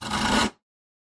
in_copingsaw_stroke_02_hpx
Coping saw cuts various pieces of wood. Tools, Hand Wood, Sawing Saw, Coping